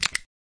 roulette_btnclick.mp3